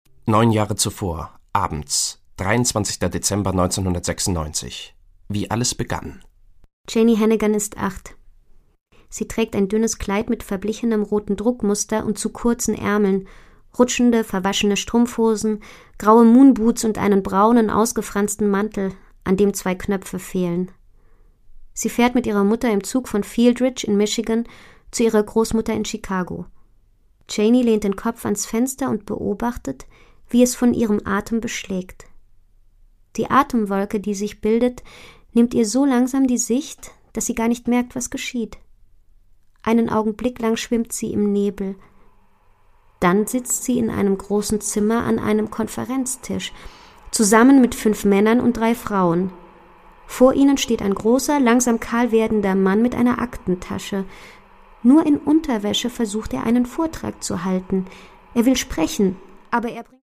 Produkttyp: Hörbuch-Download
Fassung: gekürzte Fassung
Gelesen von: Friederike Kempter, Jona Mues